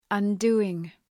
Shkrimi fonetik {ʌn’du:ıŋ}